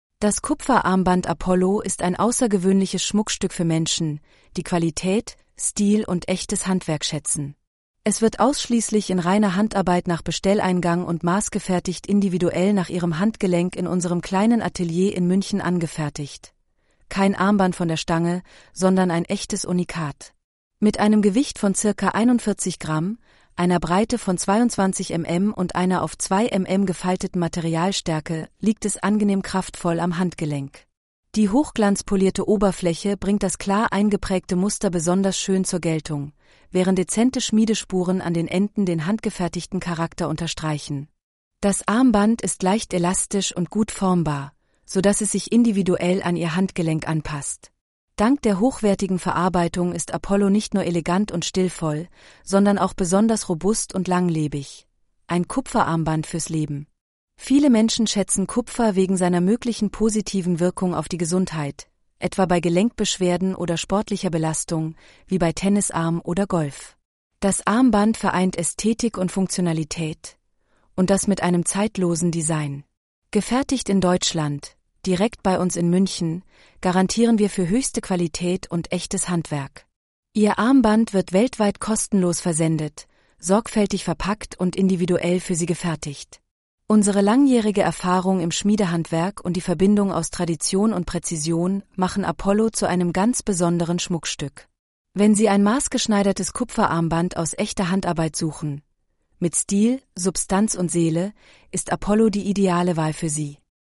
Apollo-rttsreader.mp3